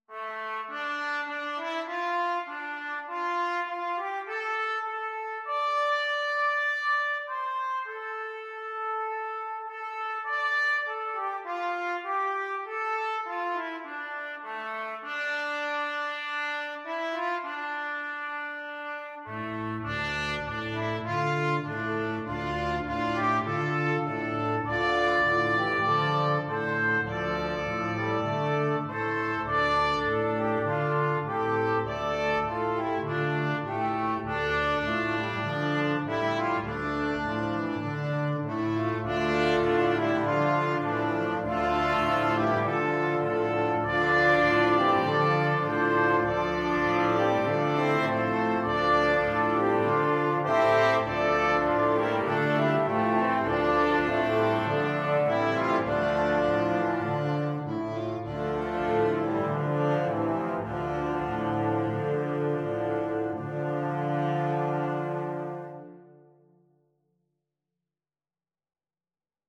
Free Sheet music for Flexible Mixed Ensemble - 6 Players
French HornOboe
French HornTenor SaxophoneClarinet
Trumpet
Alto Saxophone
TromboneBaritone Saxophone
Tuba
4/4 (View more 4/4 Music)
Sadly
D minor (Sounding Pitch) (View more D minor Music for Flexible Mixed Ensemble - 6 Players )
Israeli